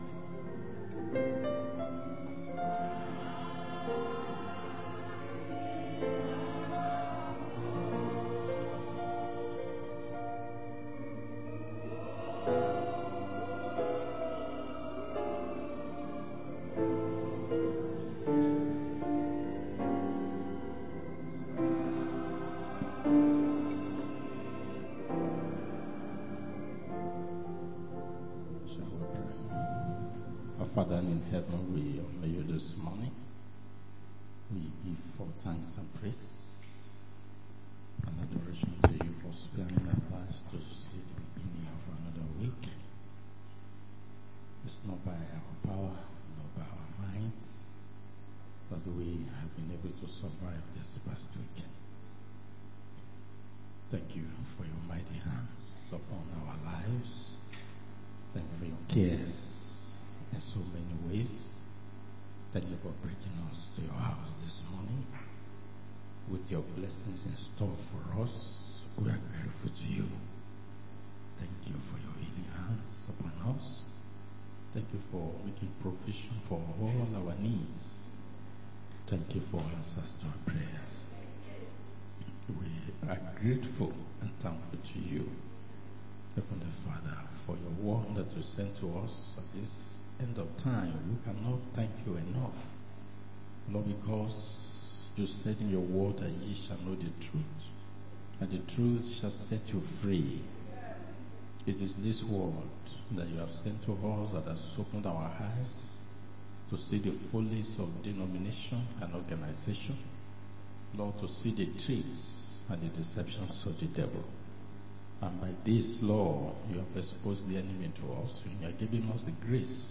Adult Sunday School 19-10-25